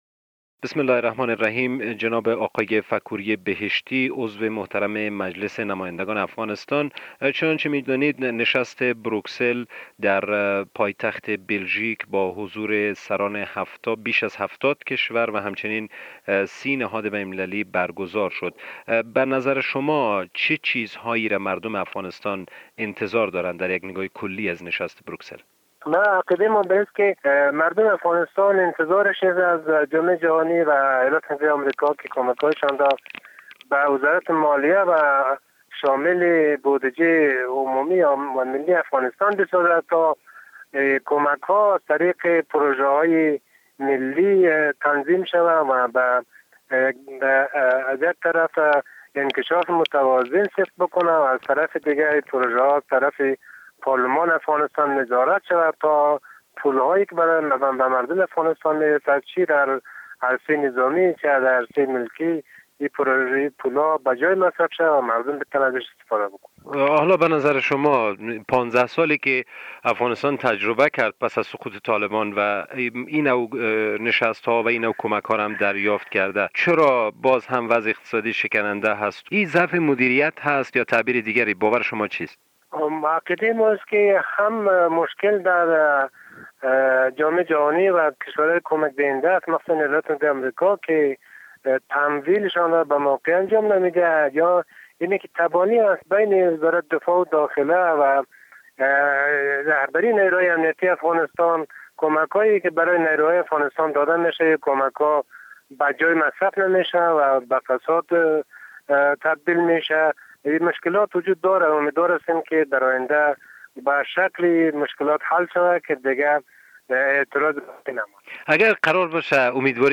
گفتگو با آقای فکوری بهشتی عضو مجلس نمایندگان افغانستان